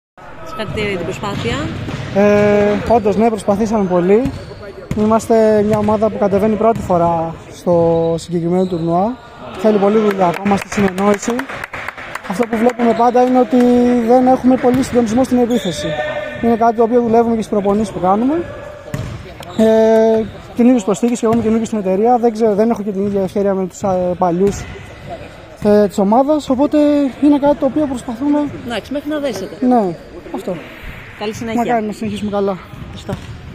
GAME INTERVIEWS:
(Παίκτης MM Marine)